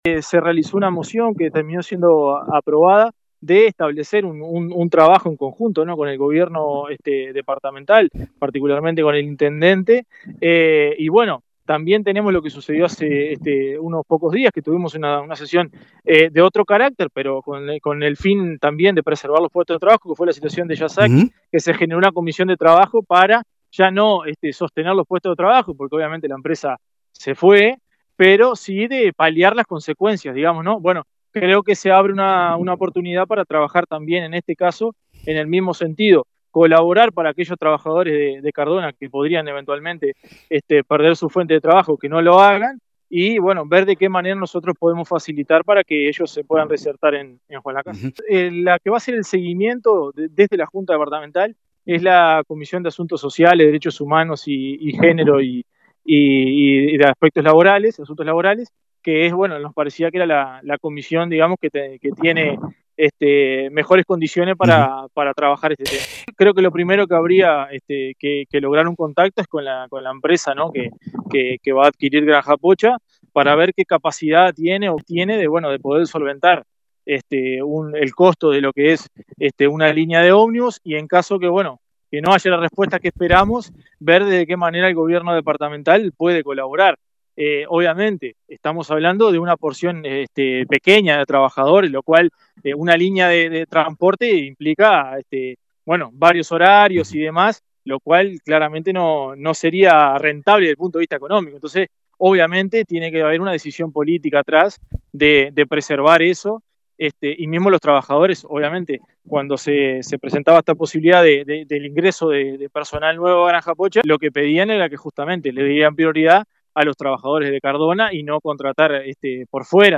En ese sentido se iniciaron gestiones con representantes de Lactalis para ver la posibilidad que se hagan cargo de los traslados o gestionar acciones con compañías de transporte con ayuda de la intendencia y el gobierno, comentó el edil del Frente Amplio Emmanuel Martínez…